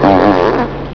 Fart
FART.WAV